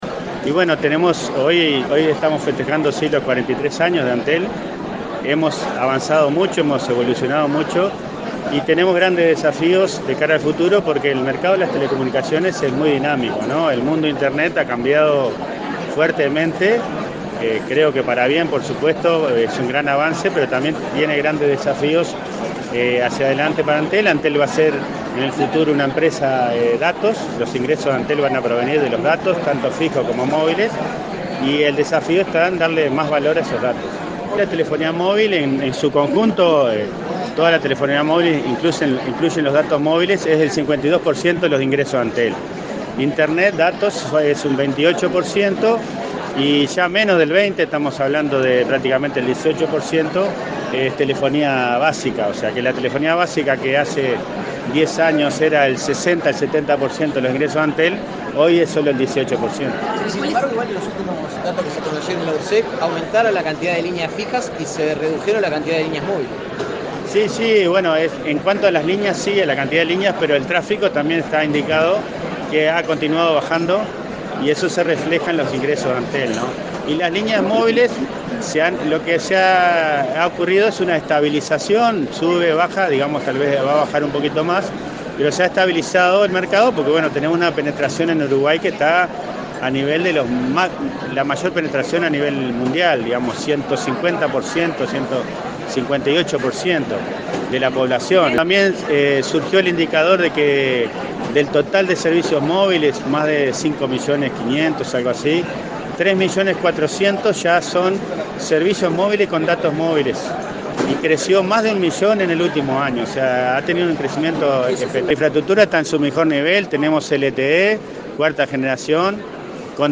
El presidente de Antel, Andrés Tolosa, sostuvo en los festejos de los 43 años del ente que la empresa dará en los próximos dos años un fuerte impulso a la tecnología de cuarta generación LTE en el interior del país, zona rural y pequeñas poblaciones, para mejorar la cobertura. Haciendo un repaso de las prioridades, dijo que hay más de 600.000 hogares conectados a fibra óptica y que en cinco años se alcanzará cobertura total.